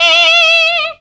FUNKY NOTE.wav